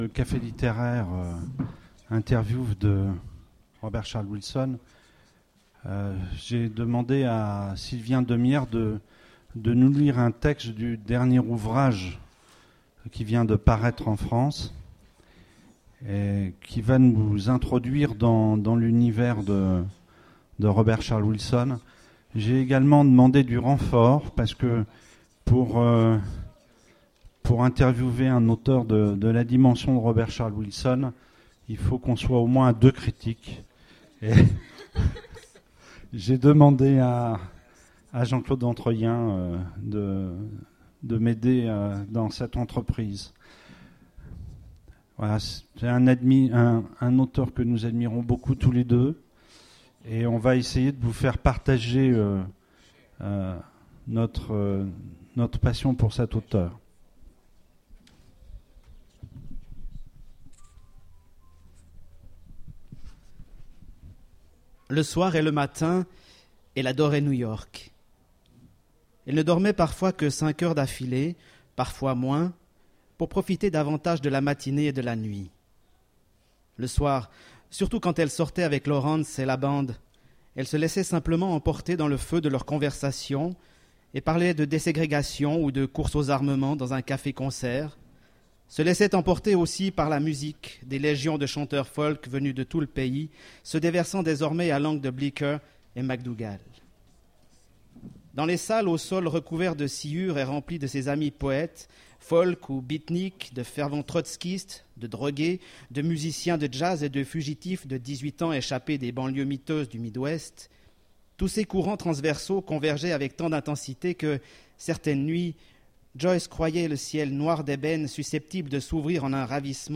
Conférence Imaginales 2010 : Robert Charles Wilson
Voici l'enregistrement de la conférence avec Robert Charles Wilson aux Imaginales 2010
Rencontre avec un auteur